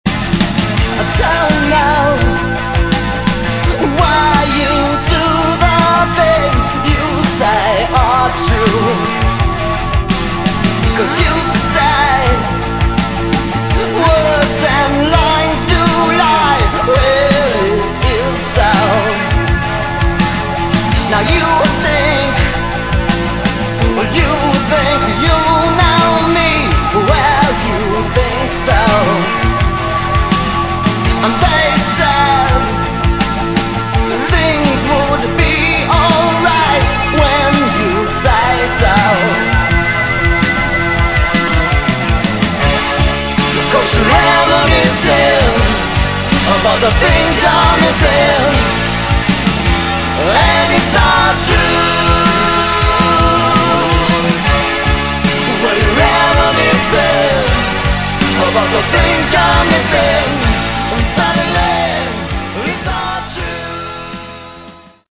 Category: AOR / Melodic Rock
lead vocals, keyboards, rhythm guitar
bass guitar, backing vocals
drums, percussion
lead guitar, spanish guitar, backing vocals